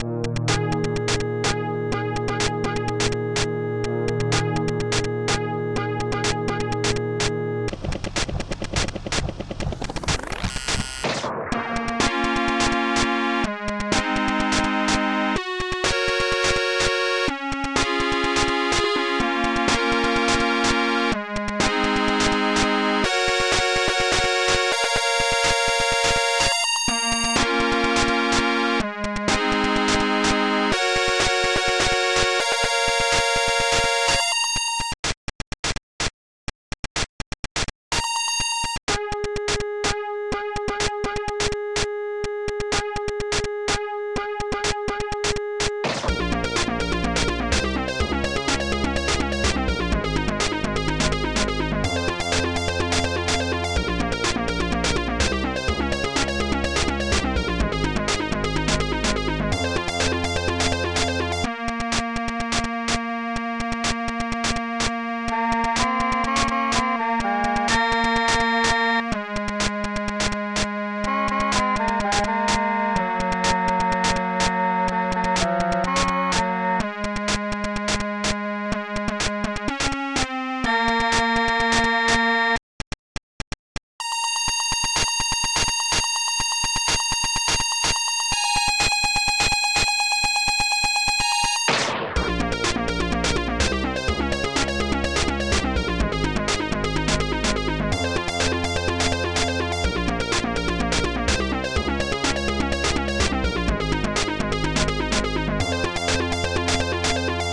Final Level music.